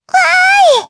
Lewsia_A-Vox_Happy4_jp.wav